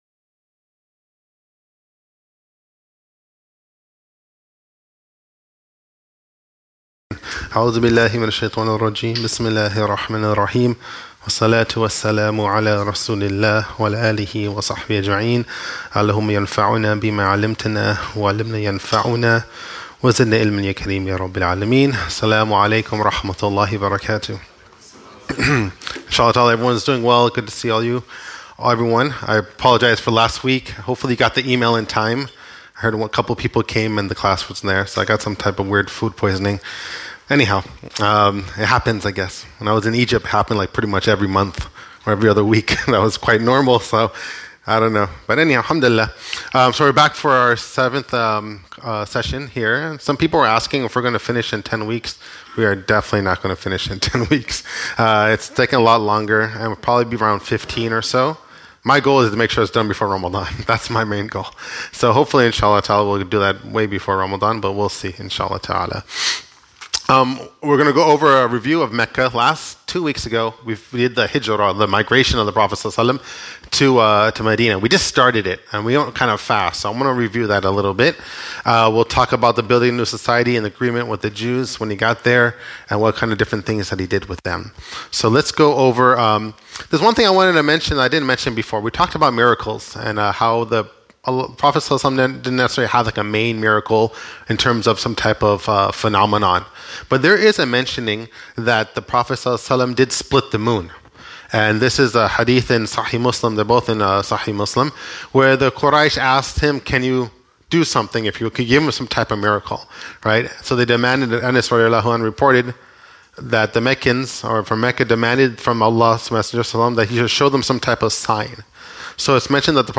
Seerah Class